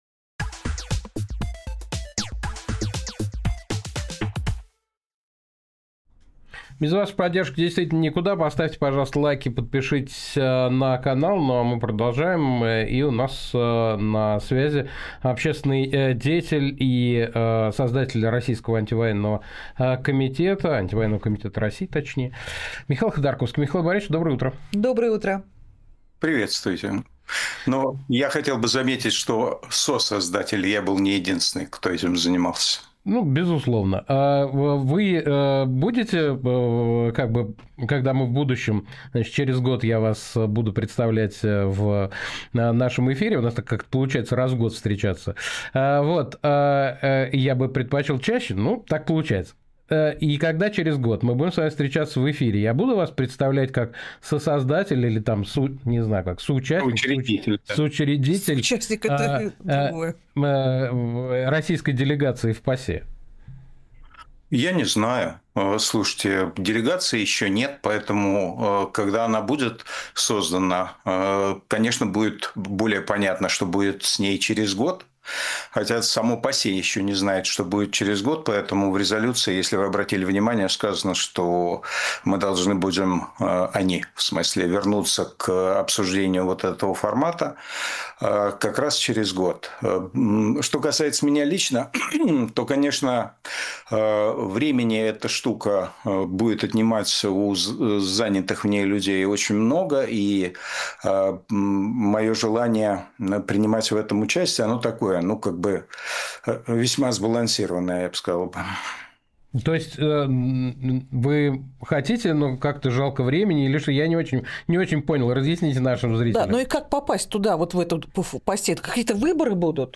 Фрагмент эфира от 13 октября